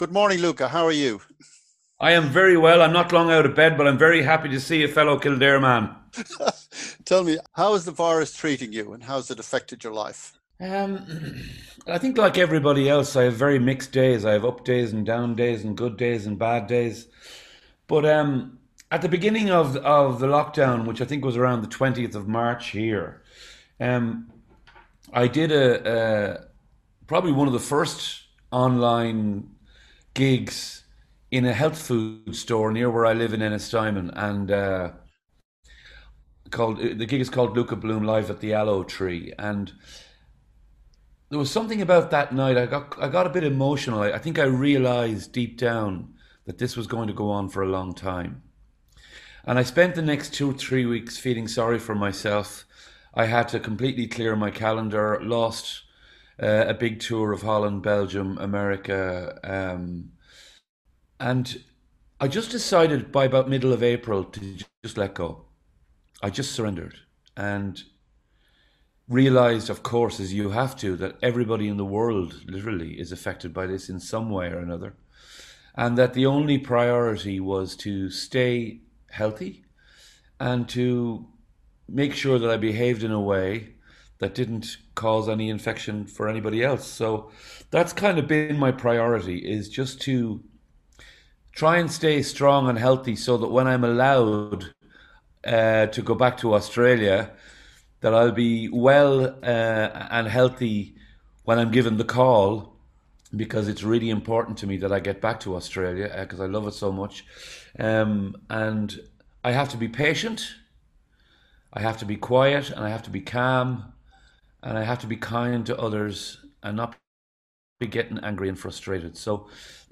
2020 08 Luka Bloom (part 1 start of interview)